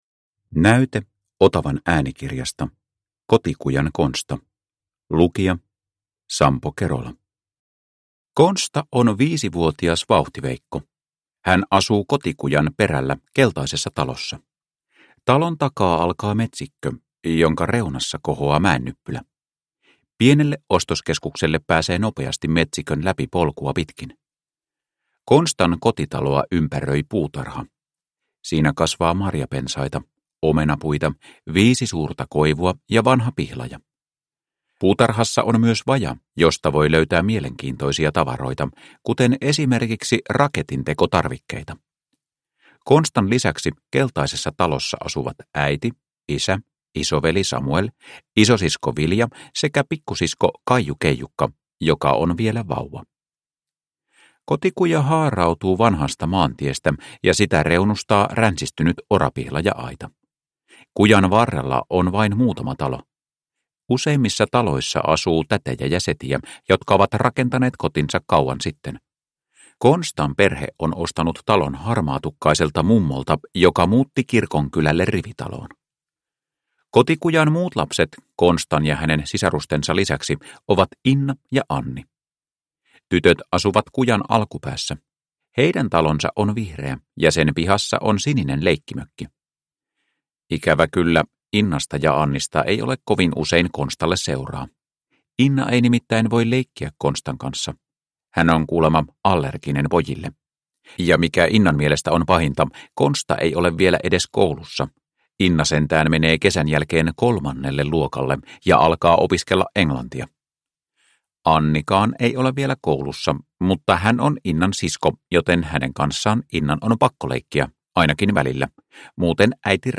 Kotikujan Konsta – Ljudbok – Laddas ner